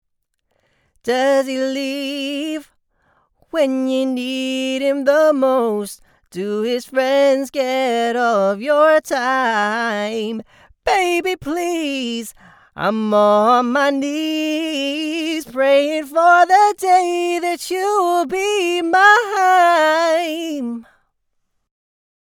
3 There are a few places where you seem to be a bit pitchy.
I can't stop focusing on the S and P sounds.